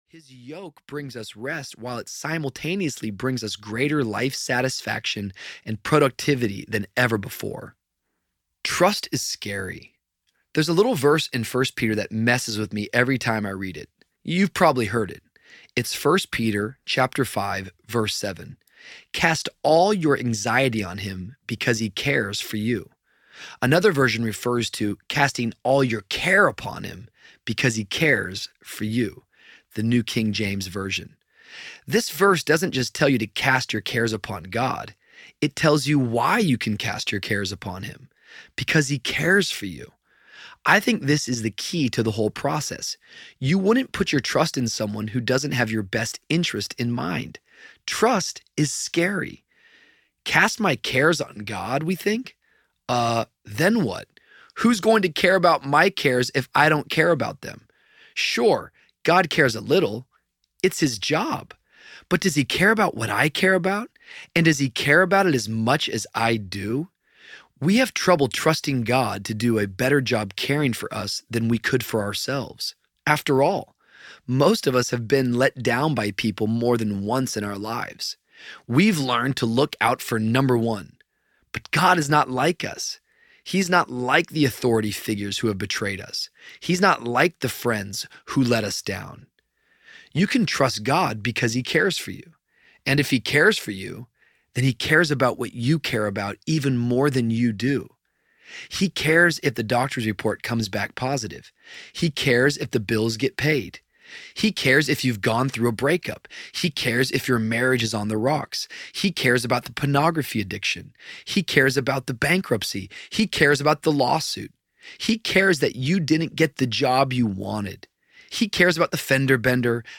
Friend of Sinners Audiobook